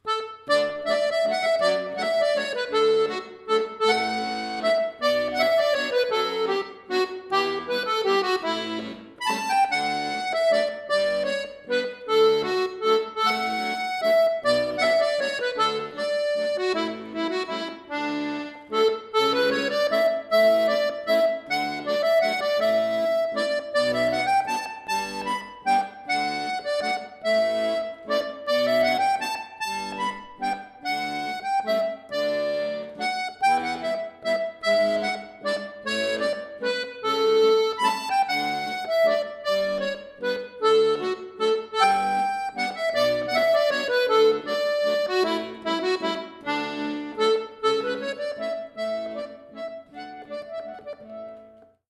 Folk
Irish